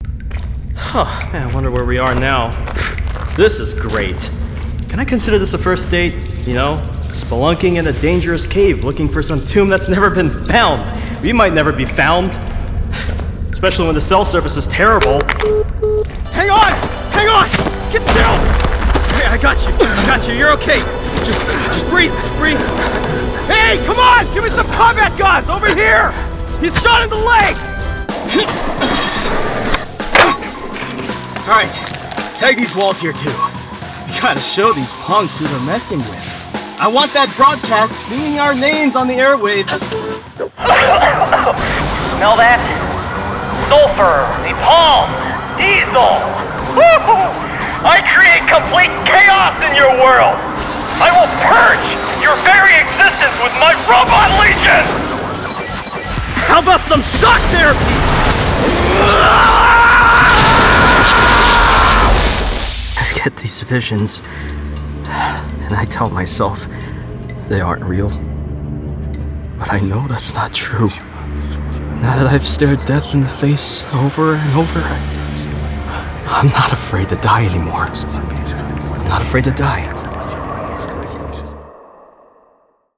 Demo
Teenager, Young Adult, Adult, Mature Adult
broadcast level home studio